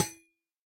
Minecraft Version Minecraft Version snapshot Latest Release | Latest Snapshot snapshot / assets / minecraft / sounds / block / copper_grate / break3.ogg Compare With Compare With Latest Release | Latest Snapshot